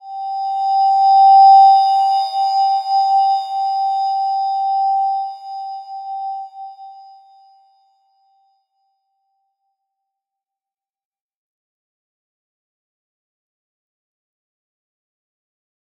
Slow-Distant-Chime-G5-f.wav